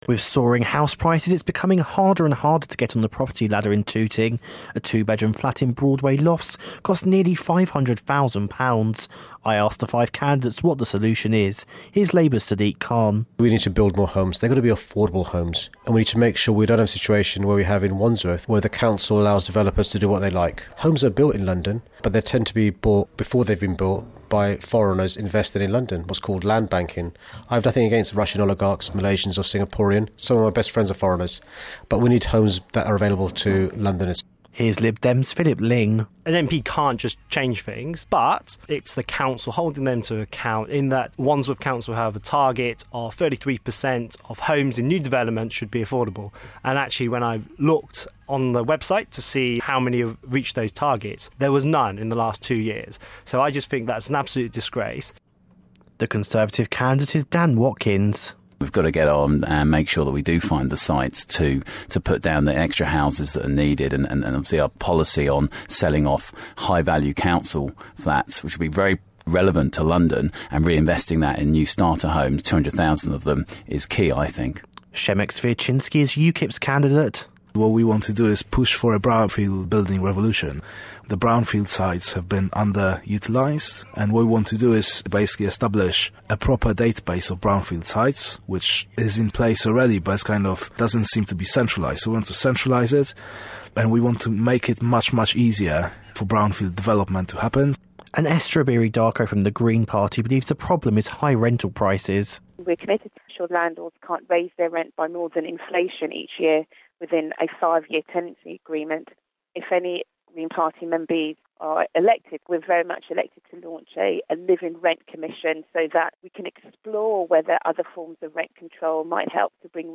Tooting election report on housing